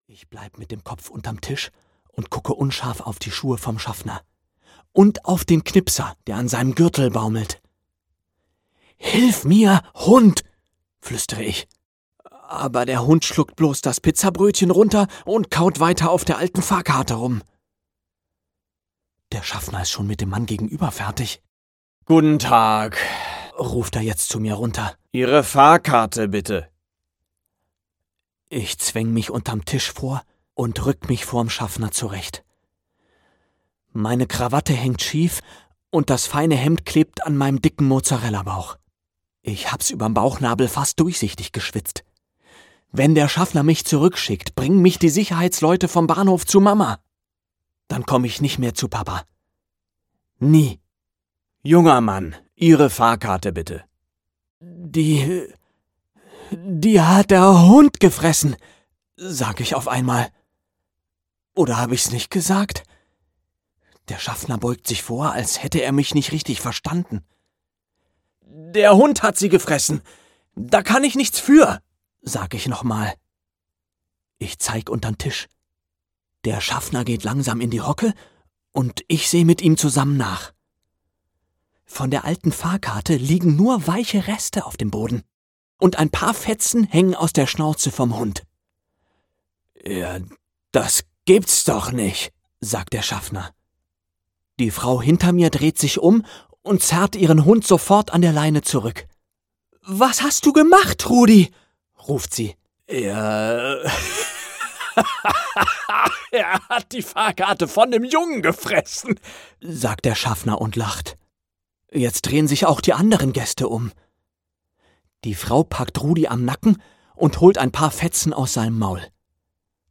Keiner hält Don Carlo auf - Oliver Scherz - Hörbuch